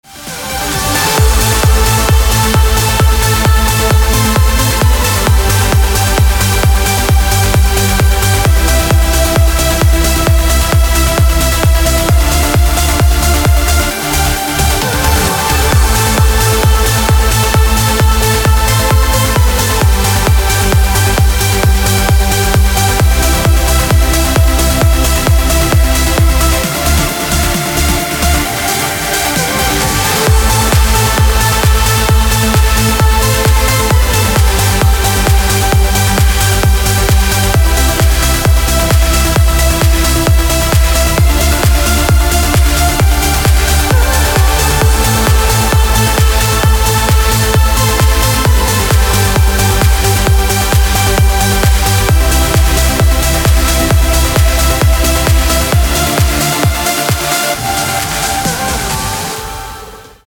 • Качество: 320, Stereo
громкие
EDM
электронная музыка
без слов
Trance
динамичные